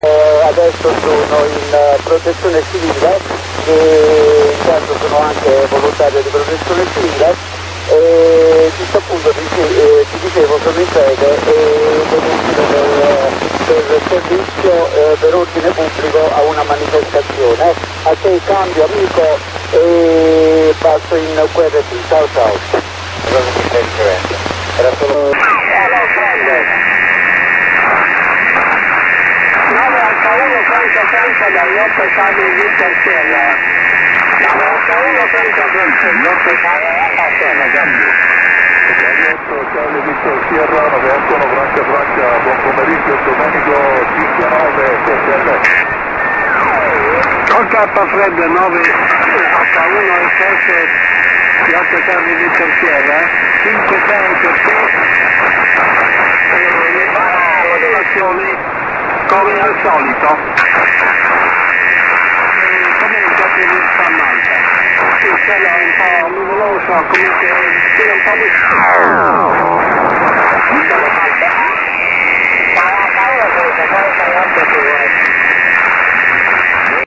VO-52 audio mp3 ...infine ecco come si ascolta FO-29 quando qualcuno decide di piazzare nel pieno centro banda downlink , 435,862 un ponte di trasferimento del link nazionale,
La prima parte del QSO e' relativa alla emissione FM su ponte ripetitore dislocato sul downlink del satellite FO-29, di seguito, si sente l'azione di disturbo del segnale portante FM sulla ricezione SSB SAT... che dire!